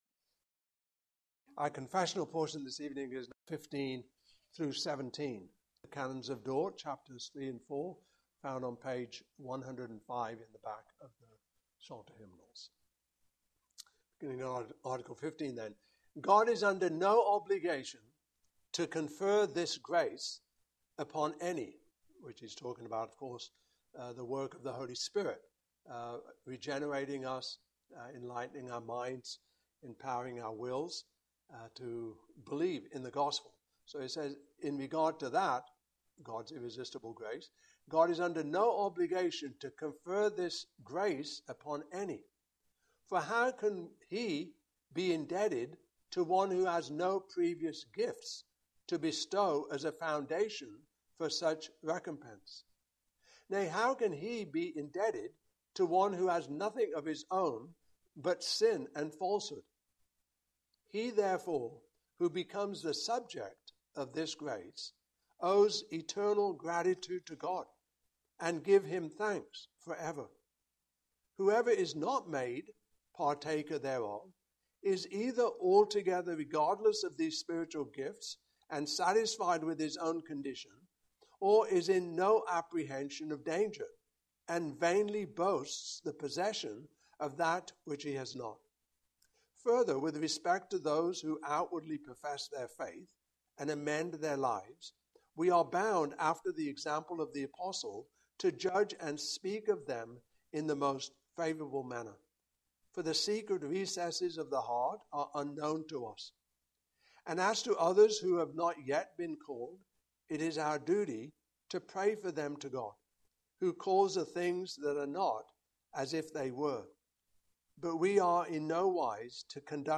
Passage: I Peter 2:1-12 Service Type: Evening Service